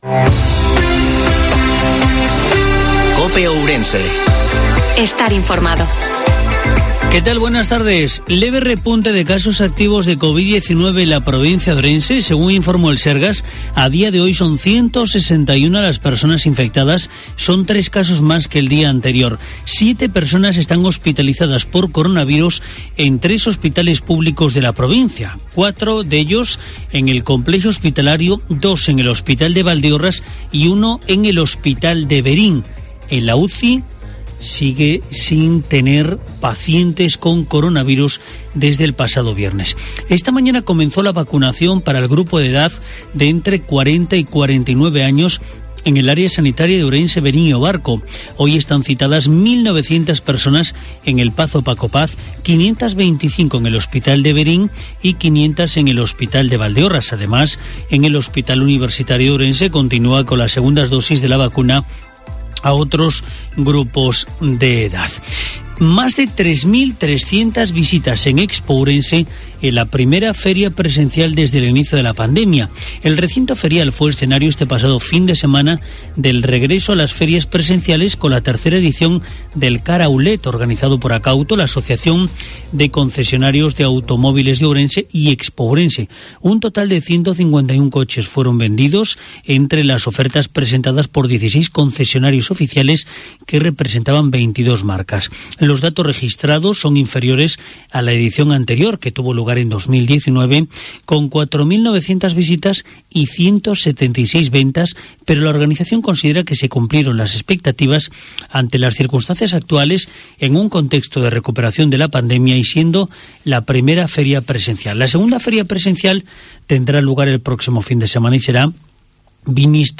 INFORMATIVO MEDIODIA COPE OURENSE